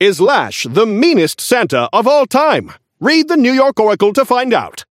Newscaster voice line - Is Lash the meanest Santa of all time?
Newscaster_seasonal_lash_unlock_01_alt_01.mp3